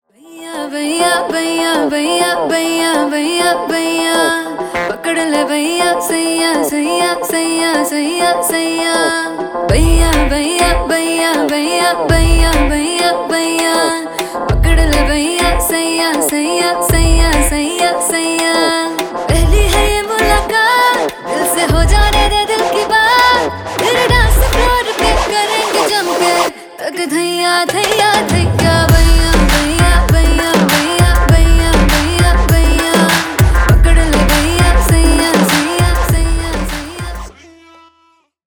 Поп Музыка
Индийские
спокойные